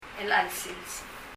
elecha el sils [ɛl ? ə ɛl sils]